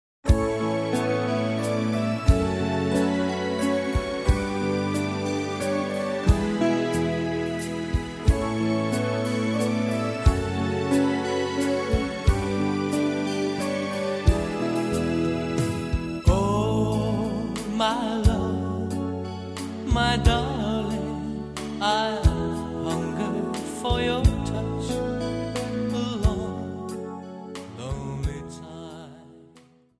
Slow Walz